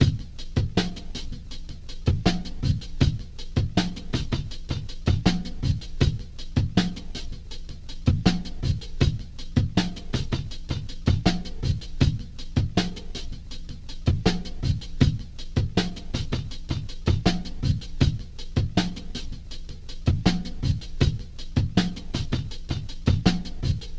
boredom 80bpm.wav